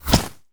bullet_impact_snow_06.wav